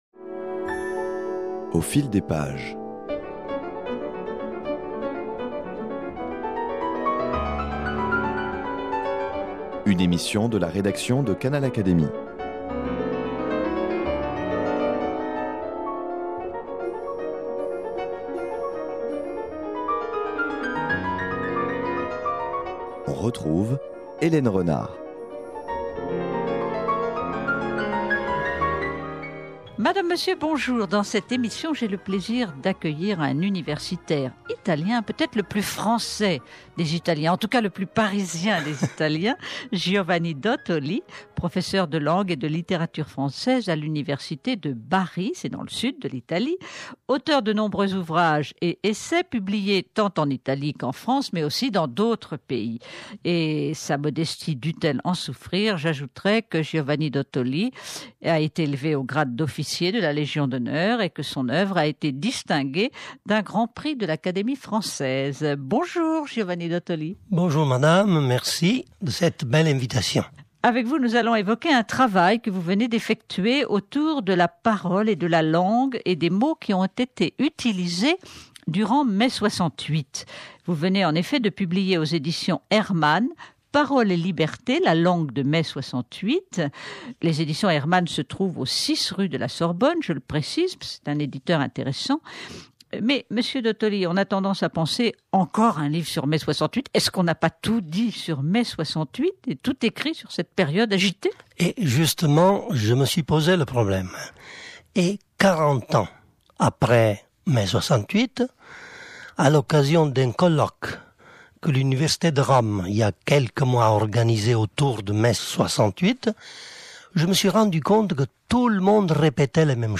Dialogue autour de son livre Parole et liberté, la langue de mai 68. Quel rôle a tenu la parole dans l’agitation et la revendication ?